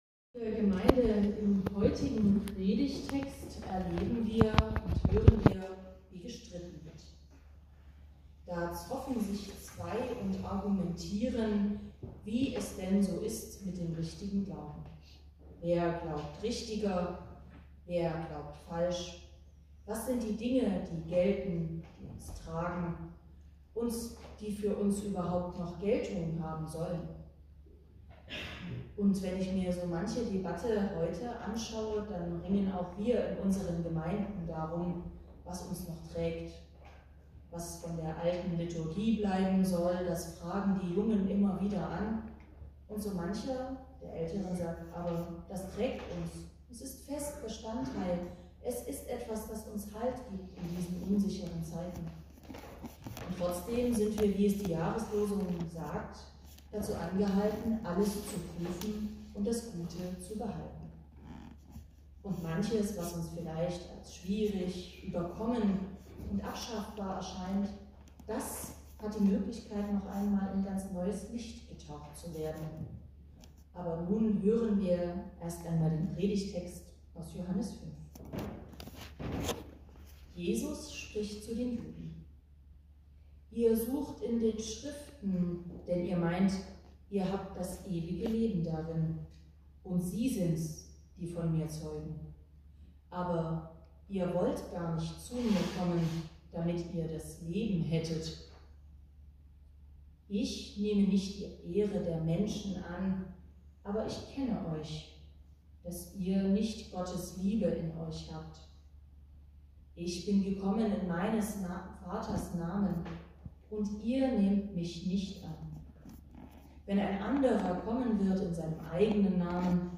Passage: Johannes 5; 39-47 Gottesdienstart: Predigtgottesdienst Wildenau « Wir glauben an den dreieinigen Gott Bist du bereit